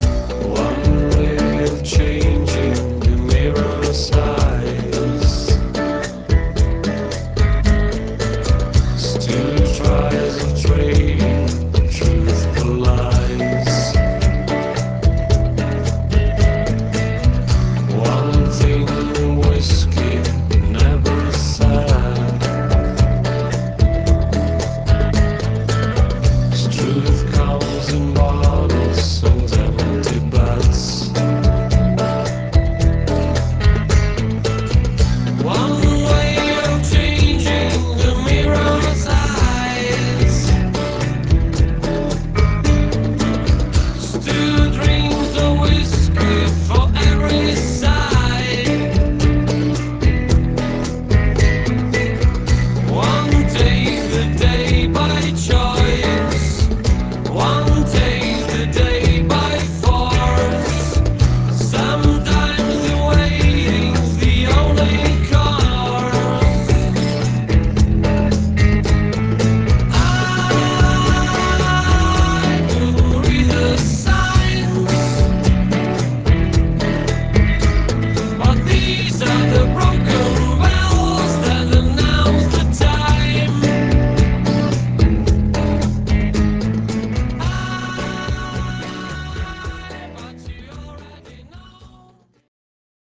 182 kB MONO